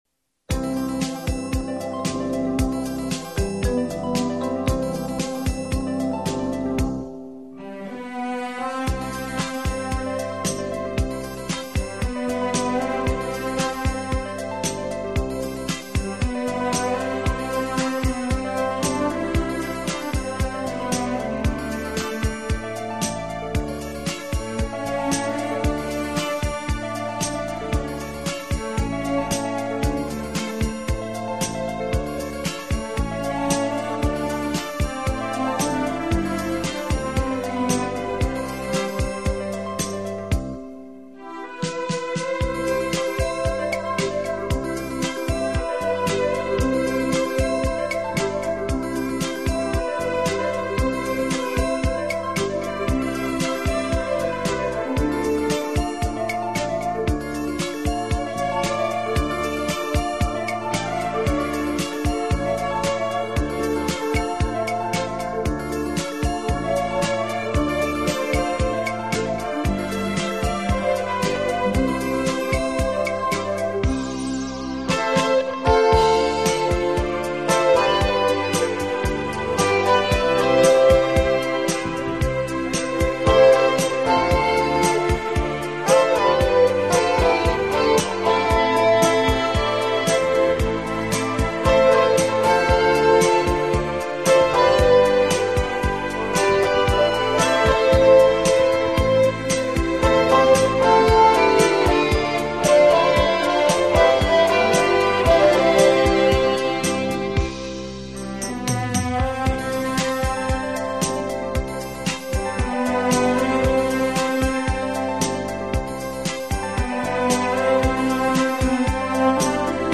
给朋友们送上一个迷人的情调音乐专辑，带领你我进入一个崭新浪漫和华
丽的音乐世界，音乐中曼妙的空间感便自然而然地完全展现！